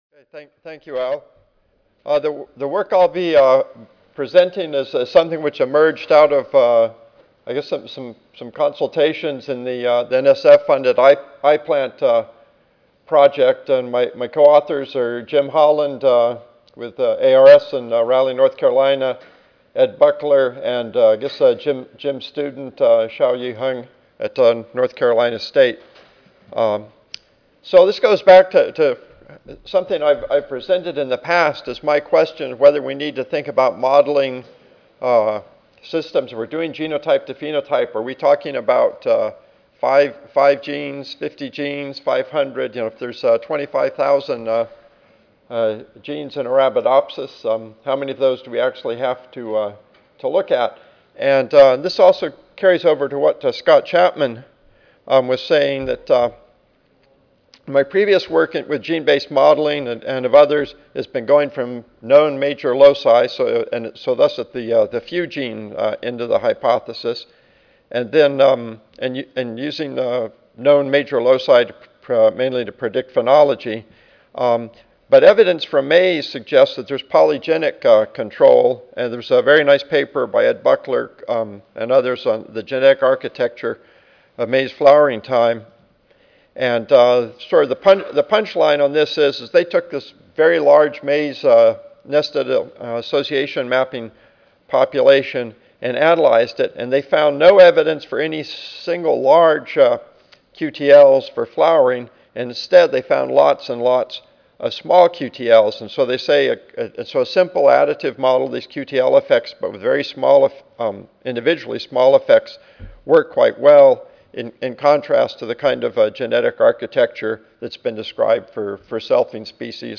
North Carolina State University Audio File Recorded presentation